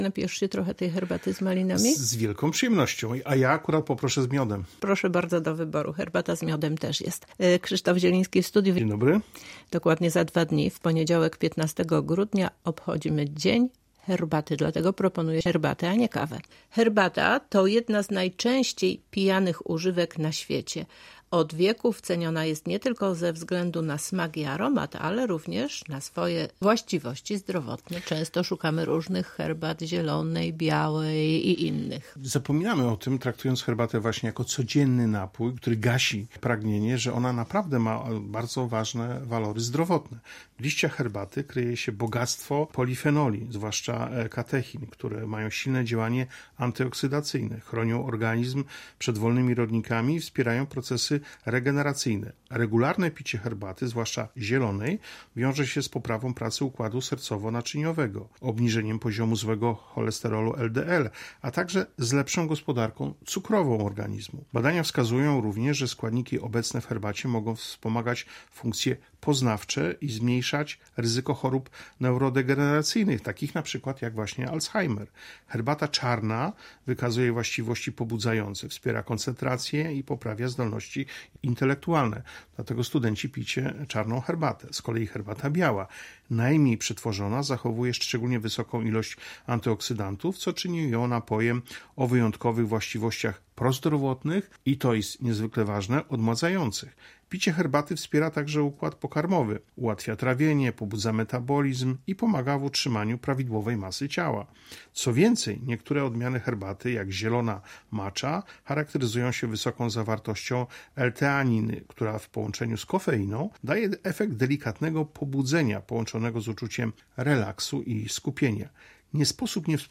Więcej na ten temat w naszej rozmowie.